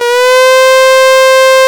STRS C4 S.wav